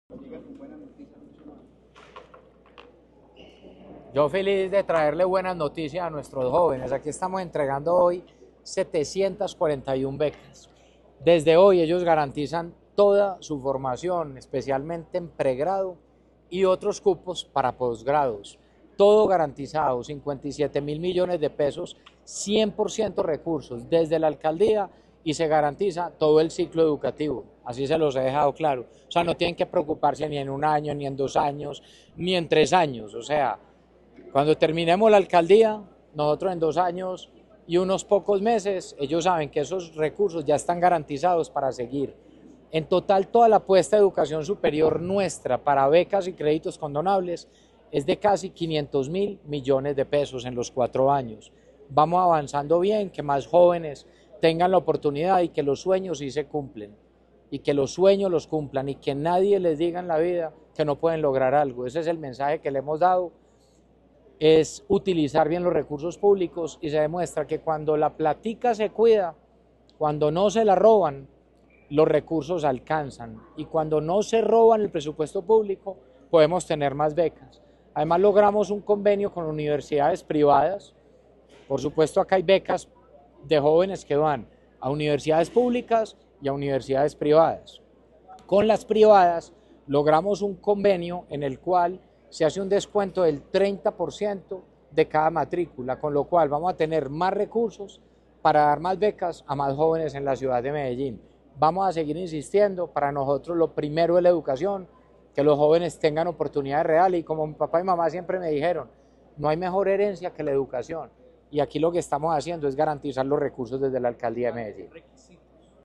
El alcalde Federico Gutiérrez Zuluaga encabezó la entrega en la Ciudadela de Occidente -C4TA-, en San Javier, donde los beneficiarios asistieron junto a sus padres y acudientes, en un acto que reflejó el respaldo familiar al inicio de esta nueva etapa académica y personal.
Declaraciones-alcalde-de-Medellin-Federico-Gutierrez-Zuluaga.mp3